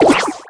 alien_shot_01.wav